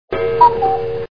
Index of /alarms
cuckoo.mp3